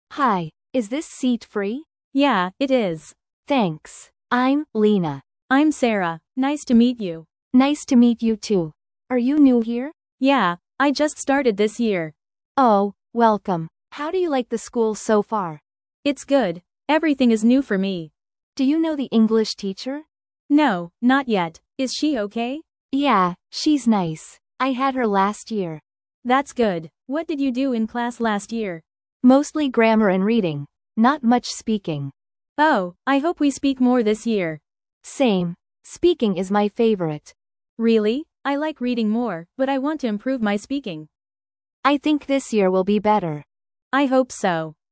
View Full Library Everyday Greetings In this lesson, you will learn how to use everyday greetings and goodbyes in real-life situations. You will listen to short conversations, complete a quiz, and read a text with audio support.